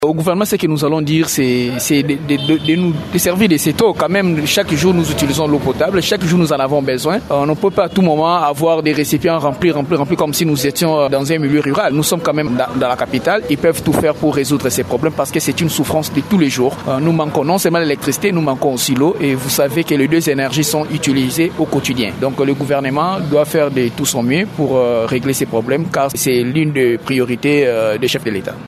Un habitant de ce coin de la capitale recommande au Gouvernement de tout mettre en œuvre pour résoudre cette pénurie.